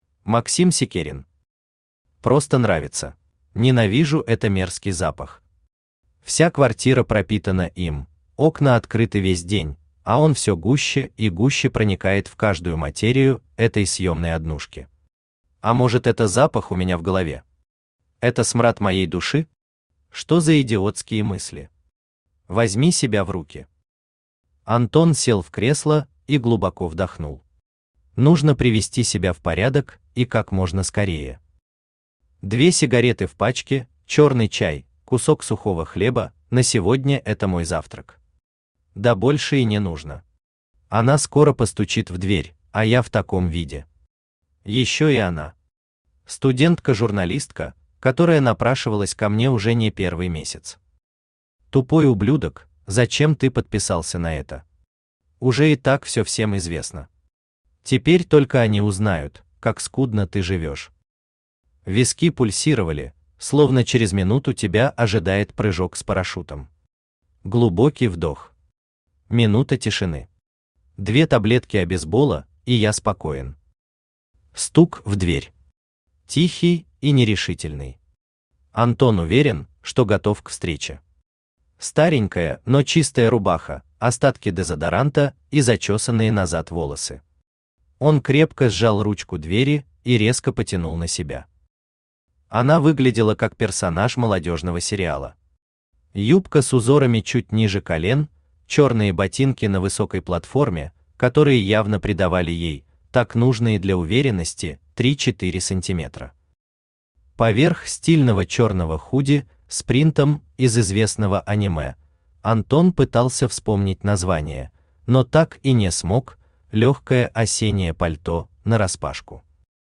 Aудиокнига Просто нравится Автор Максим Александрович Сикерин Читает аудиокнигу Авточтец ЛитРес.